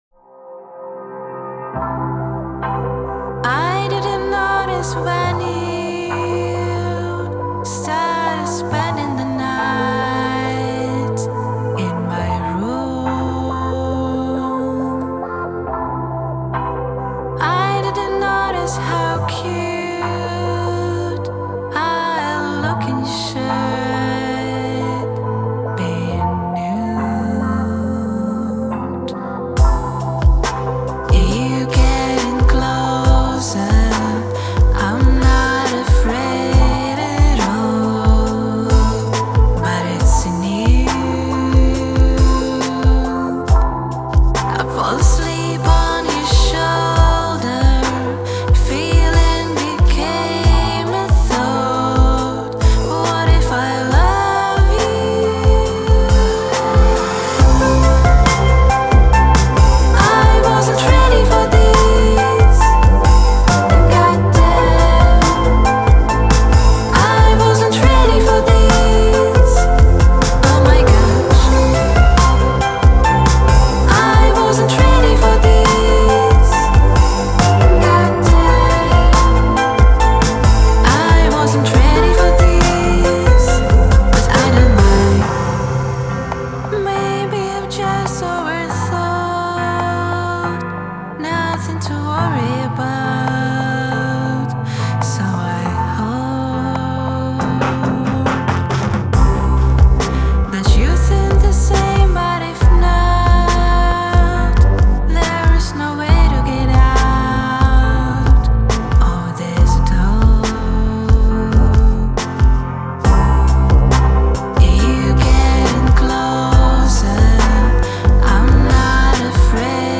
• Жанр: Українські пісні